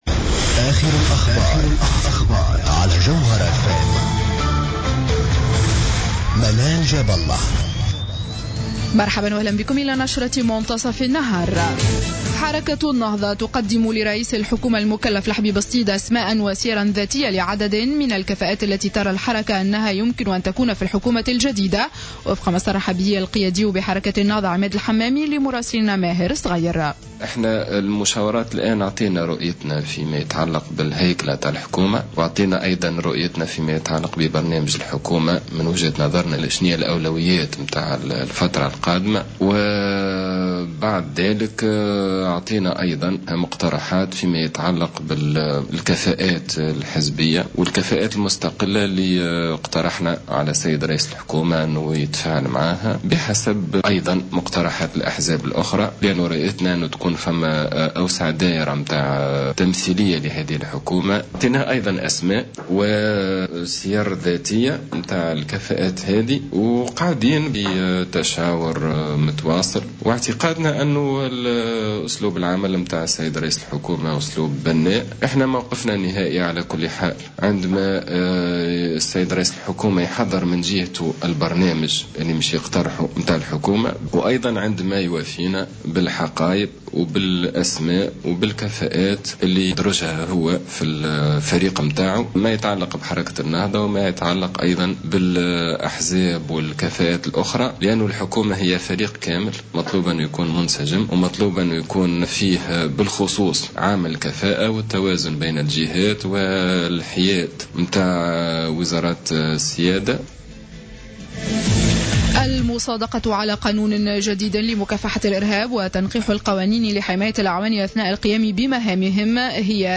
نشرة أخبار منتصف النهار ليوم الاثنين 19-01-15